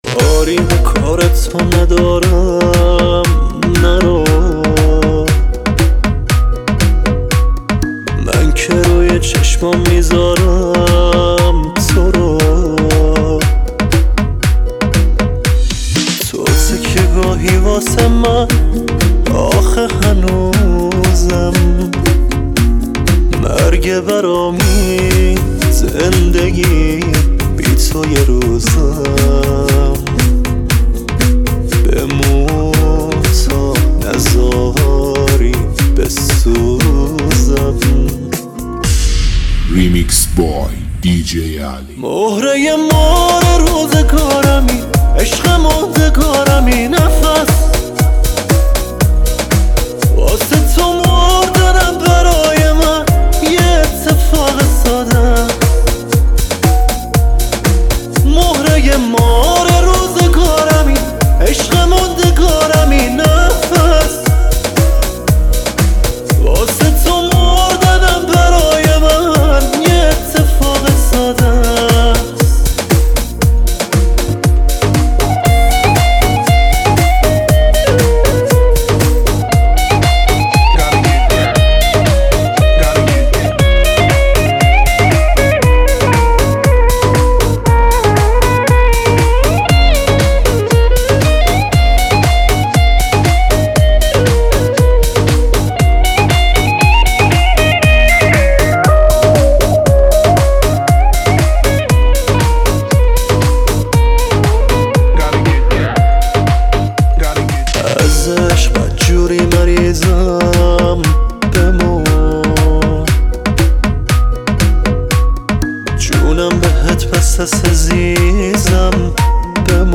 ریمیکس جدید